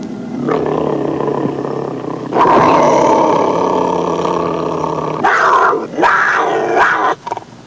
Cougar Attack Sound Effect
cougar-attack.mp3